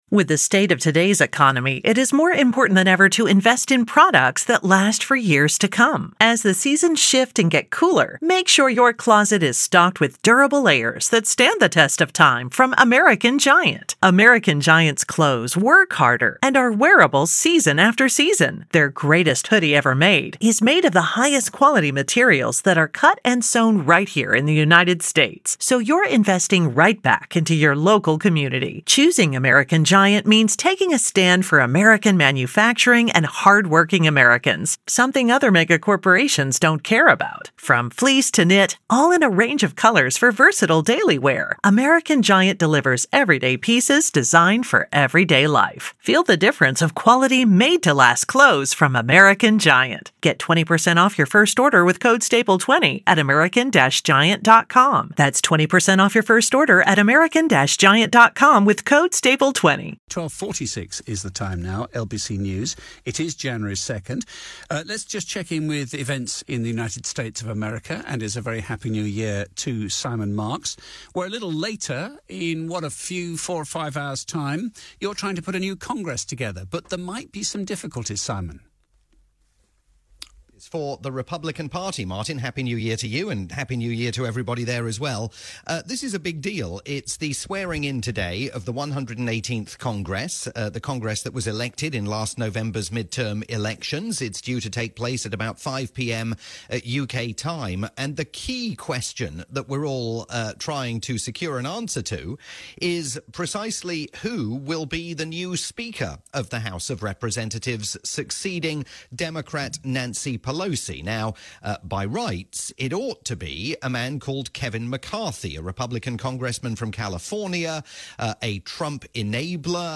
live update for LBC News